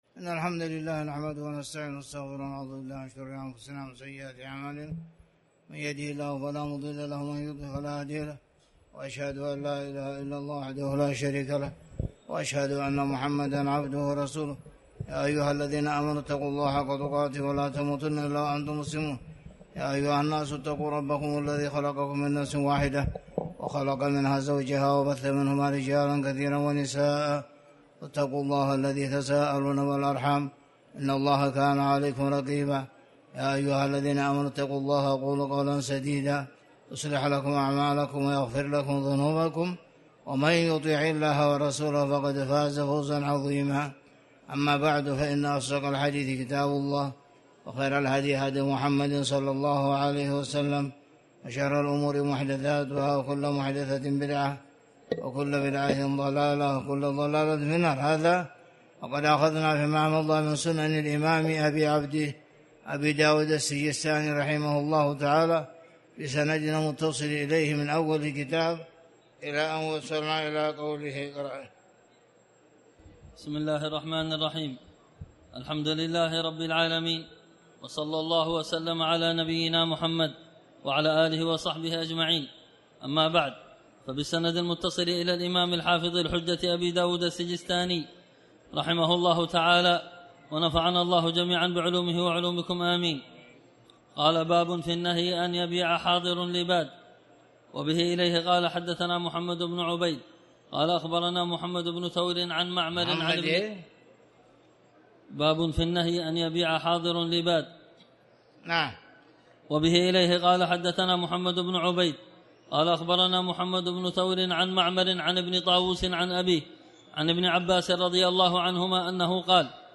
تاريخ النشر ٢١ صفر ١٤٤٠ هـ المكان: المسجد الحرام الشيخ